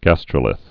(găstrə-lĭth)